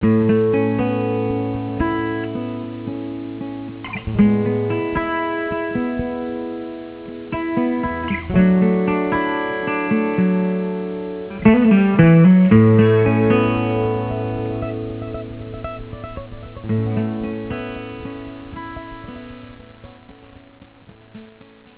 Guitarist
a set of lush, peaceful, new-age flavored instrumentals
a beautiful, ethereal Irish-flavored folk piece
Each has been compressed to 8 bit, 8 Khz sampling rate.